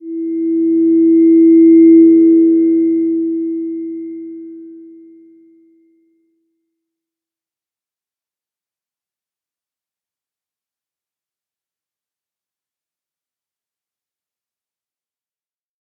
Slow-Distant-Chime-E4-p.wav